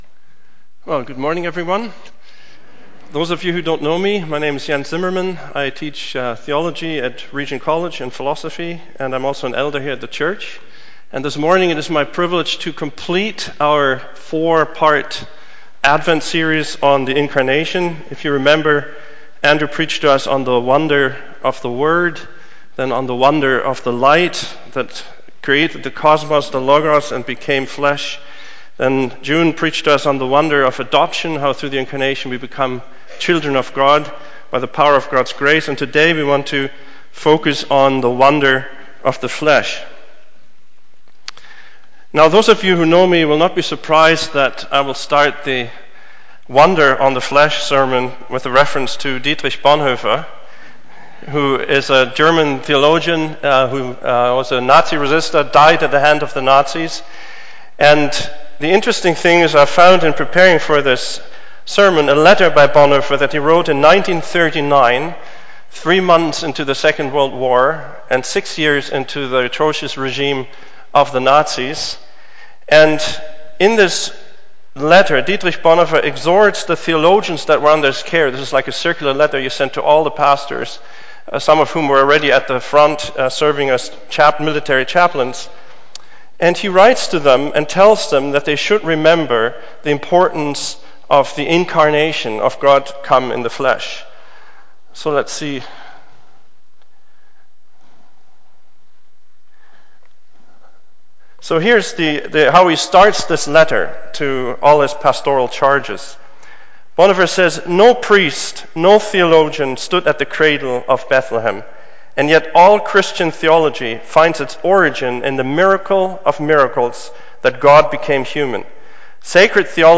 The Fourth Sunday of Advent Sermon: 2019-12-22